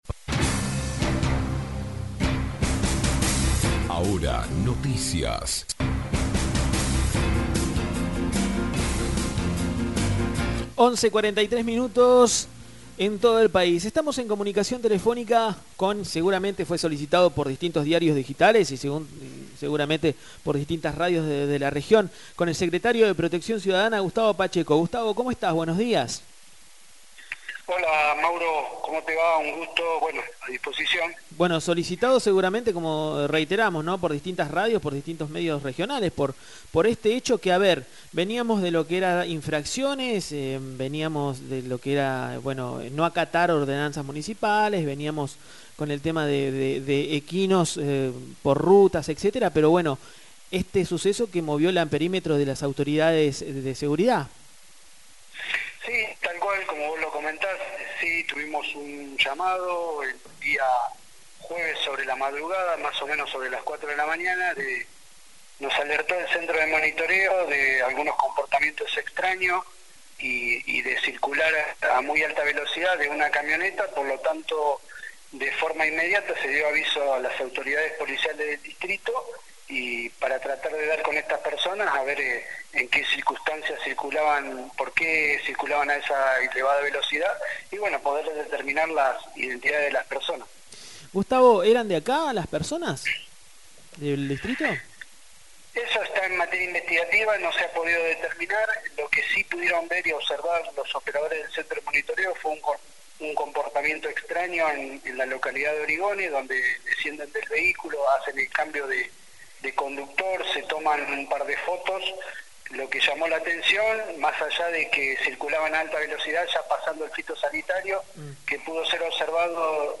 Escuchá la nota realizada al Secretario de Protección Ciudadana Gustavo Pacheco